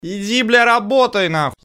короткие
голосовые